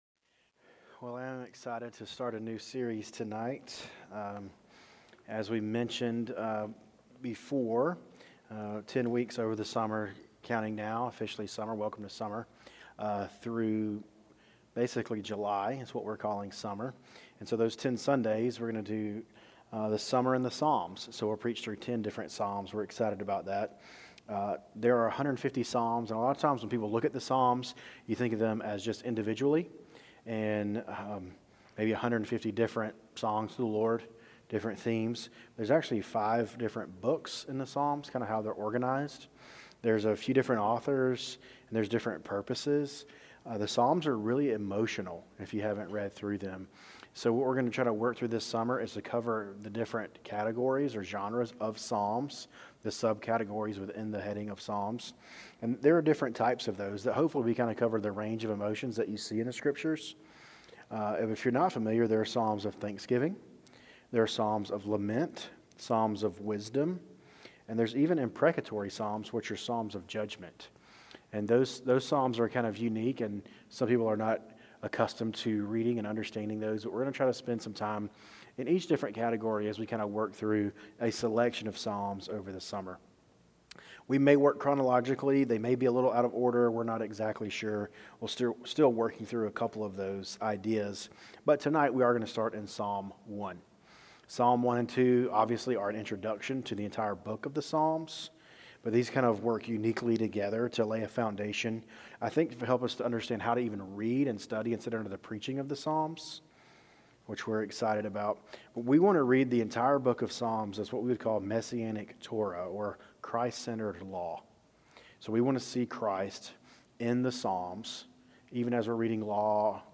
Sermons "The Two Paths" // Psalm 1